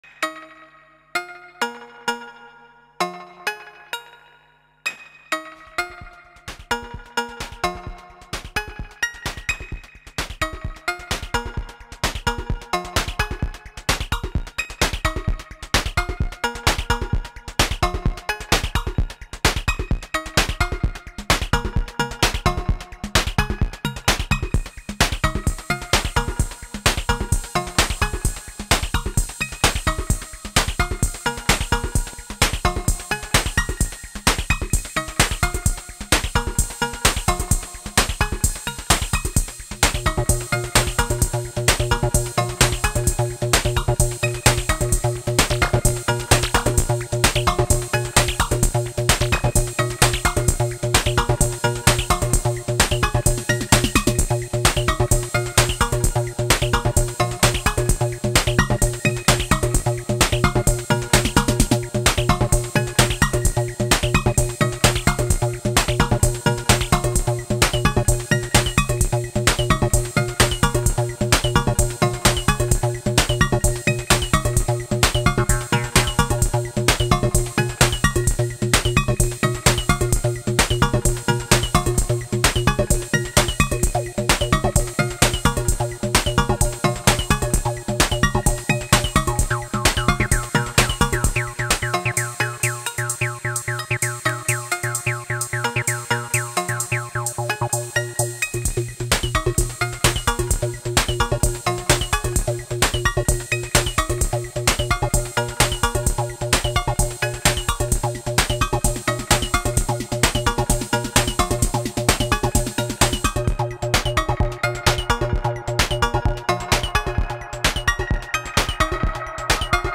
Behringer Edge on the beats with an RD6, Eurorack melody via Oberkorn sequencer, bass line td3mo. Edge is the master clock here.